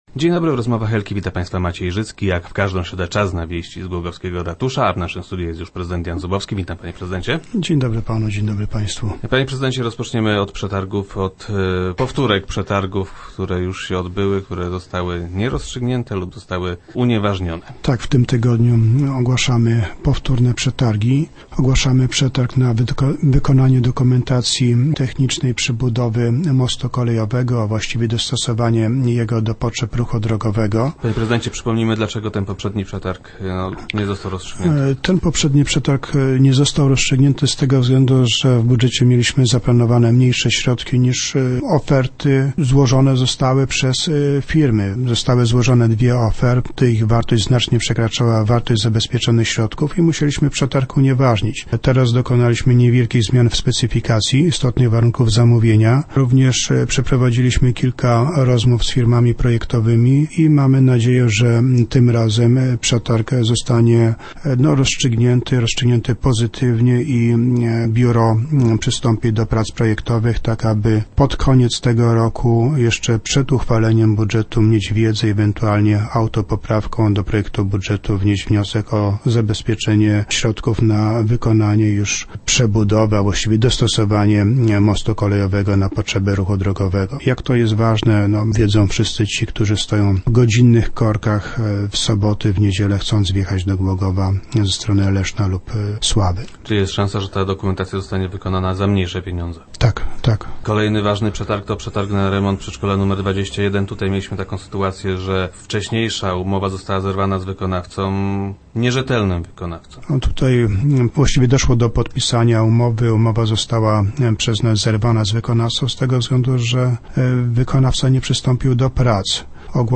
Chcemy pod koniec tego roku, jeszcze przed uchwaleniem budżetu, mieć wiedzę o kosztach inwestycji - stwierdził prezydent Zubowski, który był gościem Rozmów Elki.